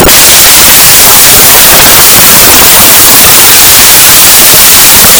Loudest Earrape On This Site Botón de Sonido